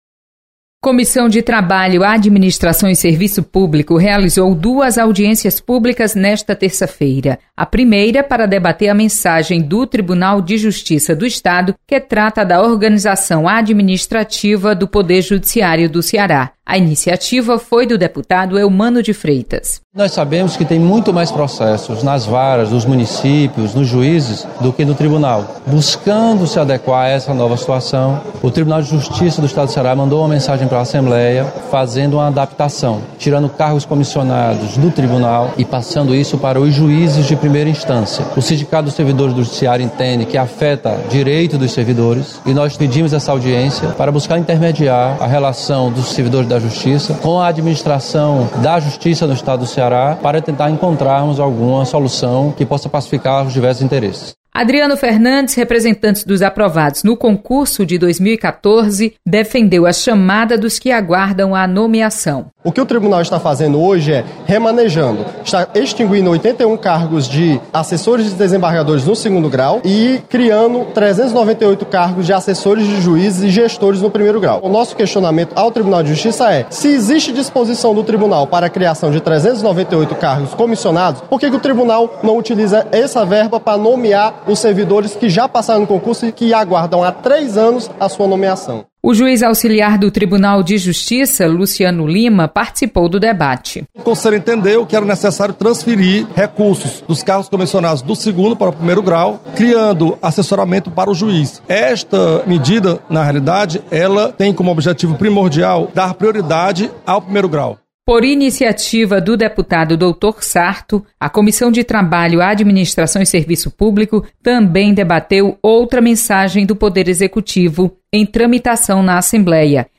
Acompanhe o resumo das comissões técnicas permanentes da Assembleia Legislativa. Repórter